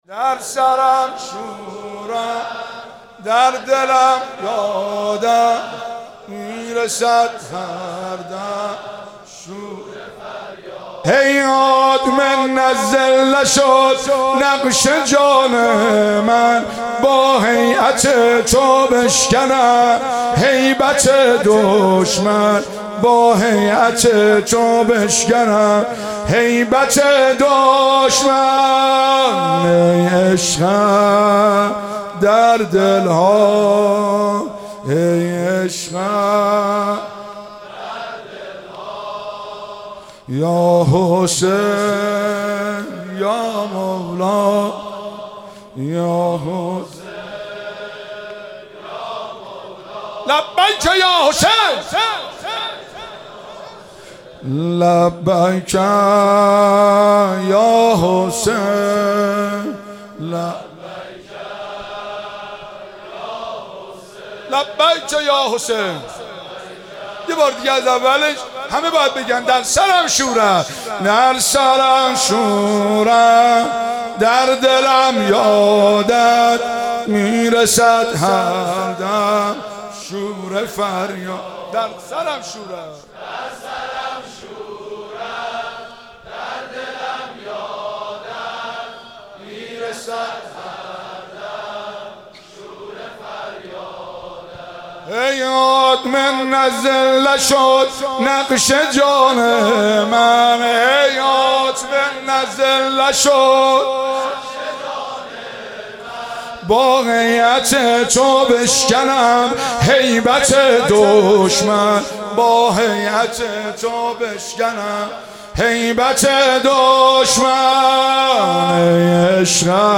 شب دوم محرم الحرام 95/ مسجد مقدس جمکران
شور و زمزمه